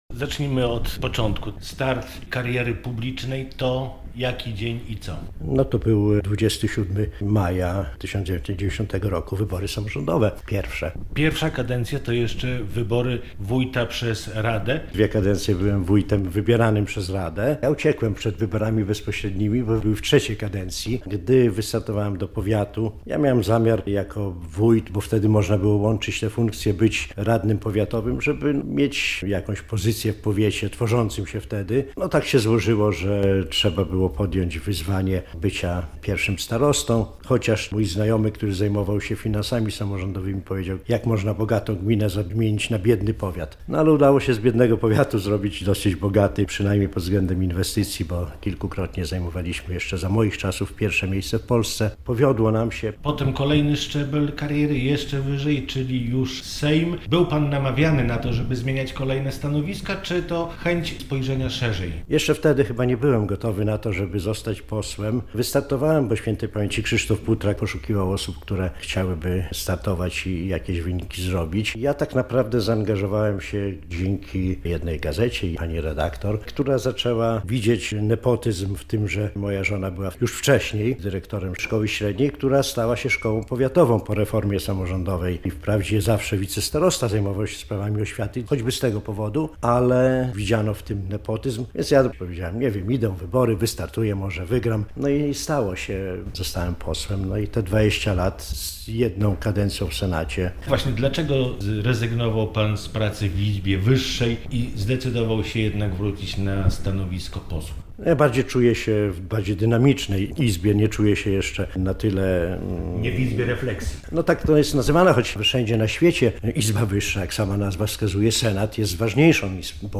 Z Jackiem Boguckim rozmawia